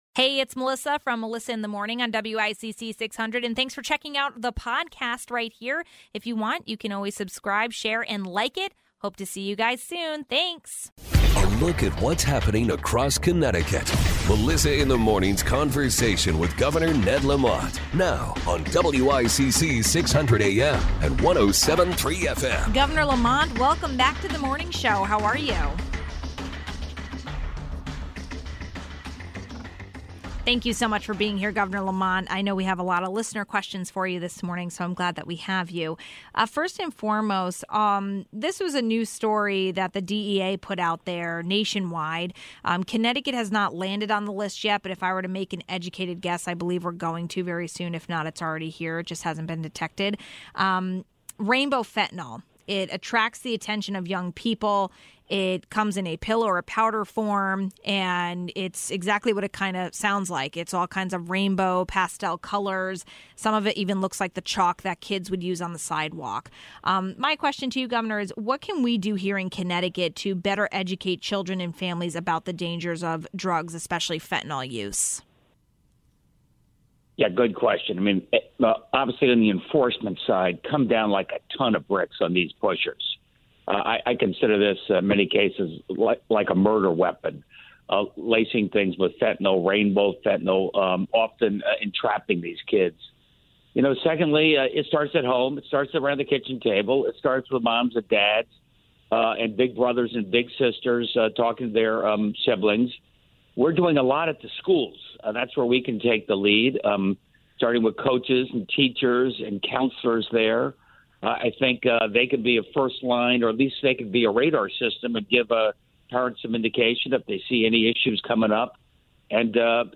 Governor Ned Lamont came on to answer listener questions. We spoke with him about schools and transportation, but we also touched on drug education in schools. What can be done about teaching students and families about fentanyl?